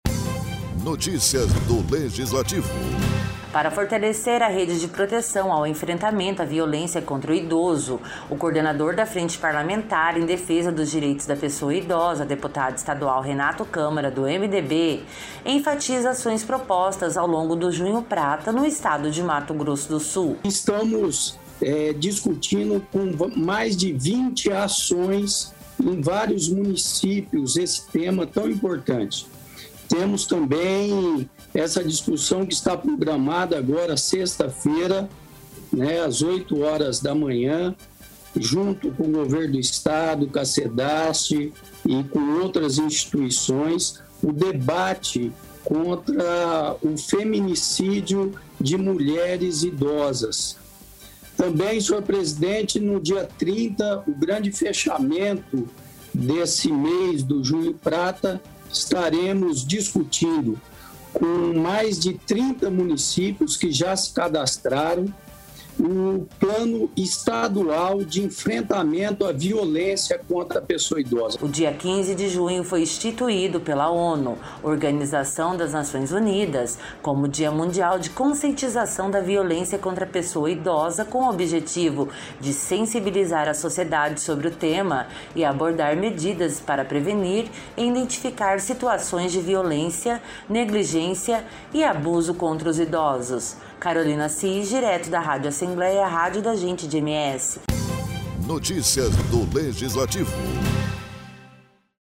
O deputado estadual Renato Câmara (MDB), enfatizou durante a sessão plenária desta terça-feira, ações de combate ao enfrentamento à violência contra a pessoa idosa.